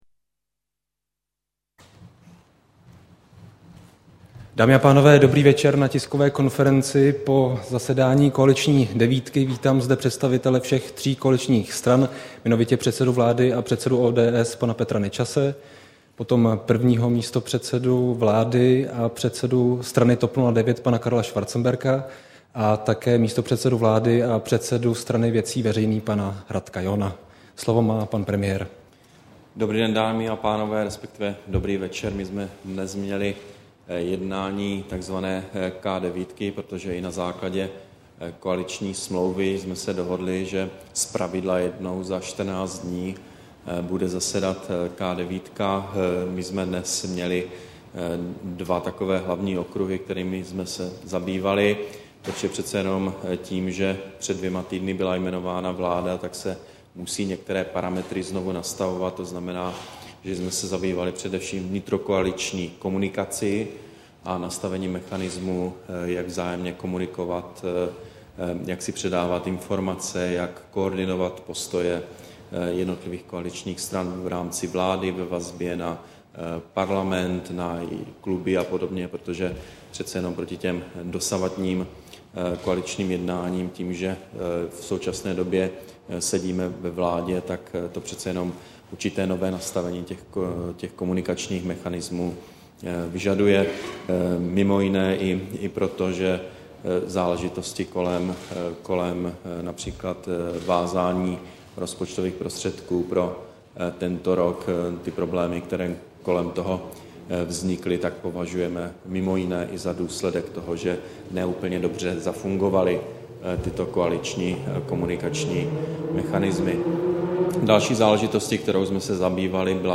Tisková konference po jednání K9, 28. července 2010